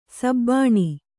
♪ sabbāṇi